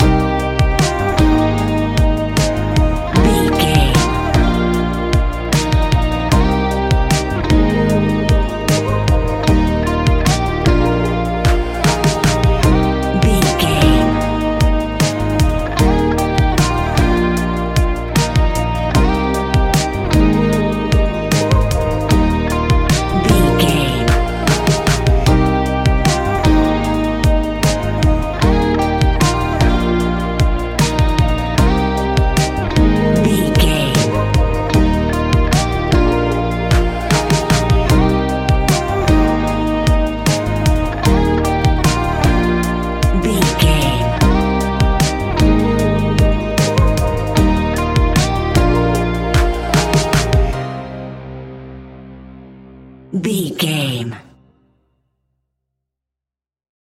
Ionian/Major
D♯
ambient
electronic
new age
downtempo
pads
drone